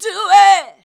DO IT.wav